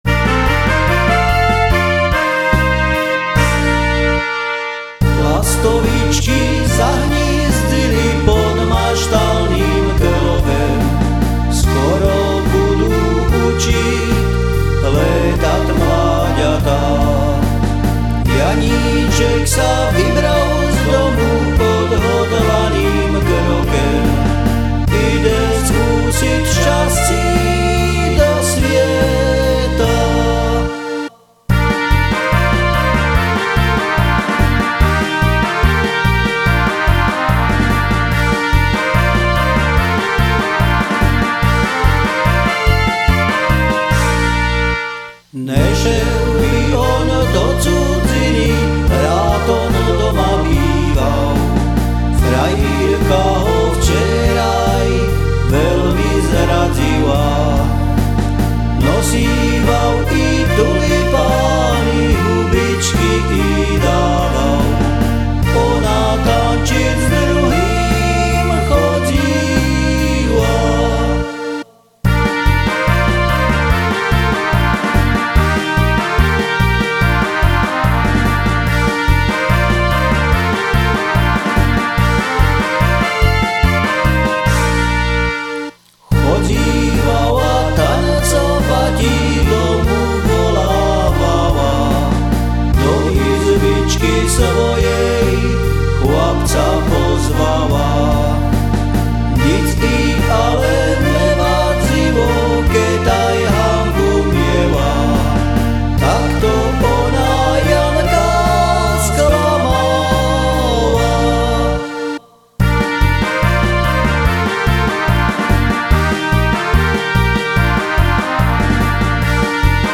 CD2 - pokus o druhý čardáš ...